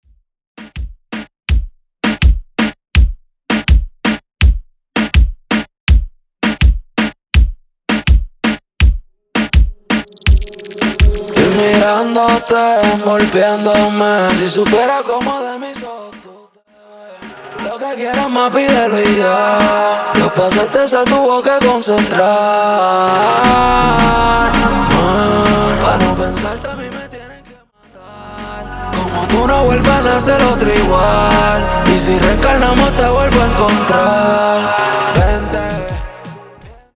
Intro Acapella Dirty